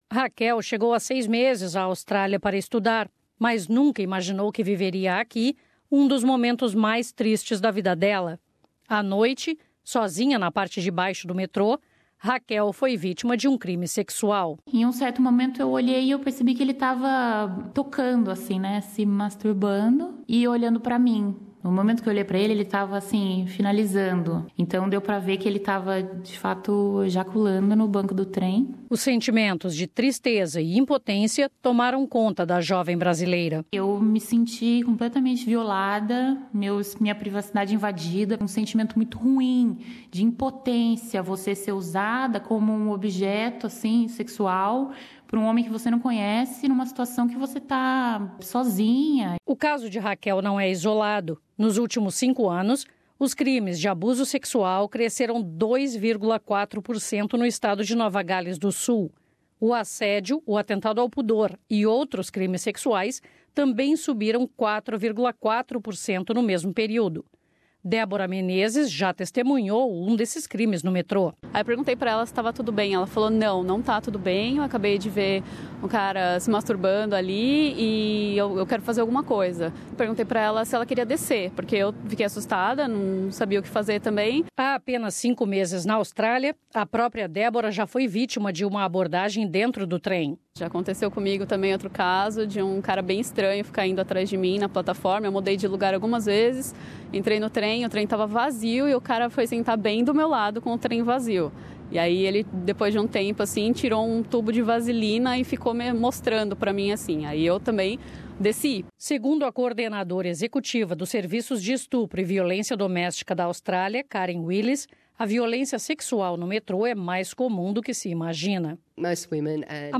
As brasileiras relatam os casos de assédio e abuso nesta entrevista à SBS.